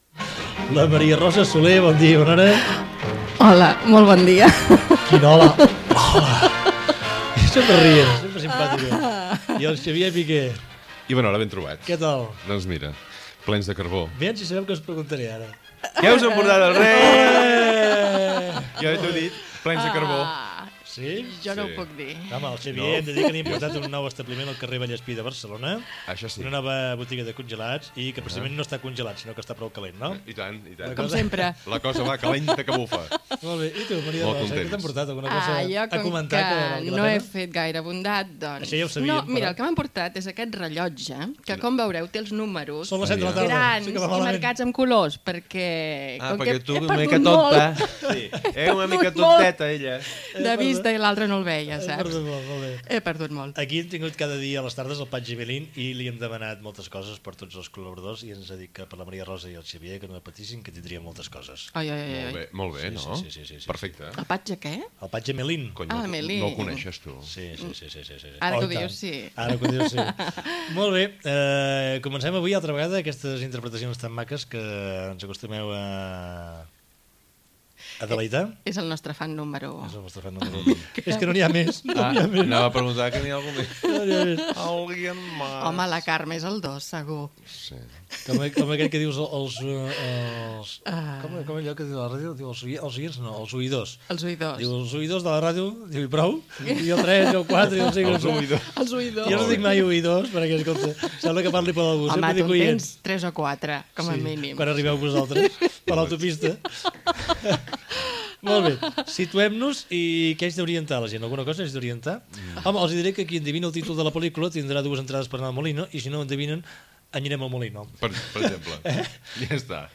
espai "Assaig en viu" amb una ficció sonora cinematogràfica, trucada per encertar de quina pel·lícula es tractava i comiat del programa Gènere radiofònic Entreteniment